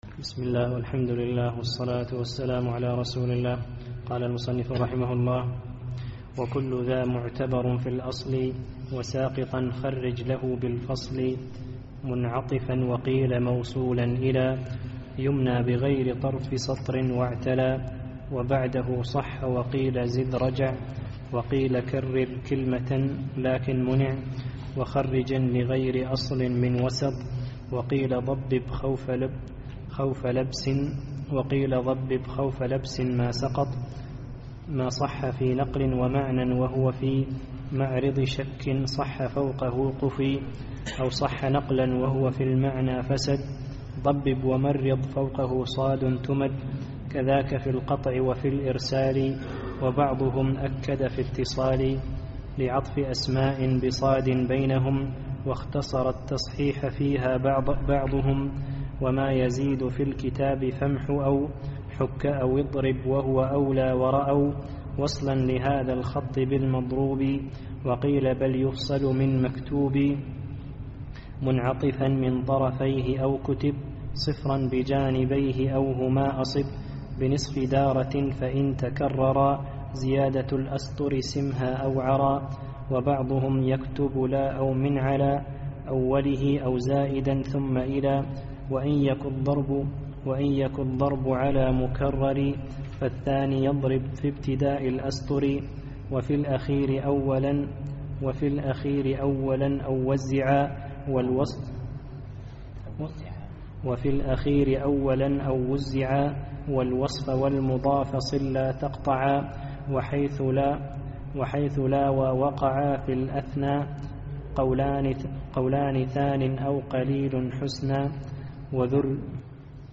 الدرس الحادي والعشرون